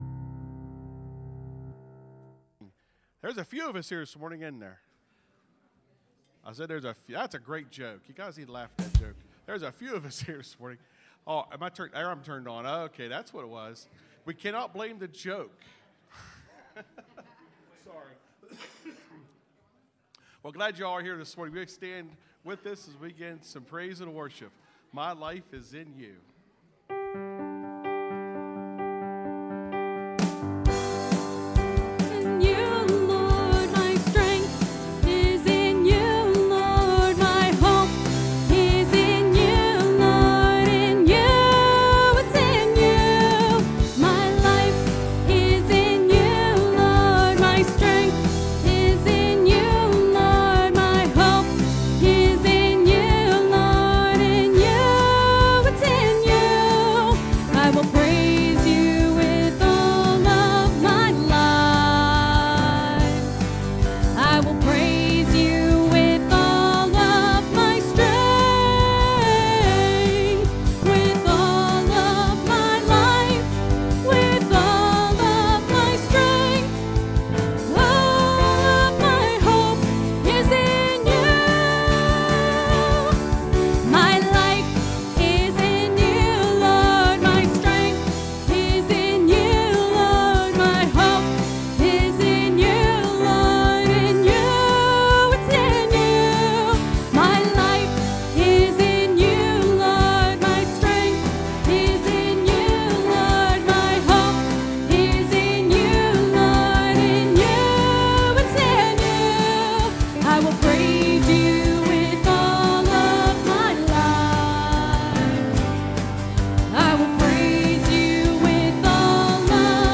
(Sermon starts at 25:15 in the recording).